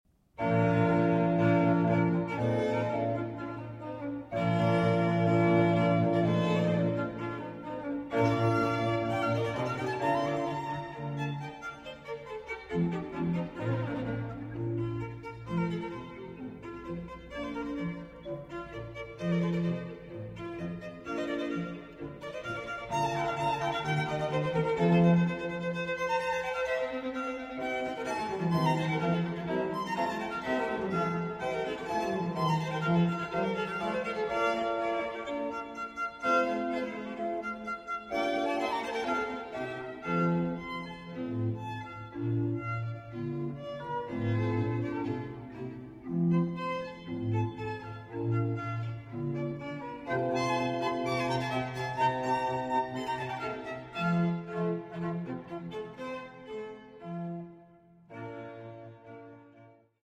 B flat major